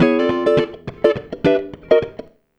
104FUNKY 11.wav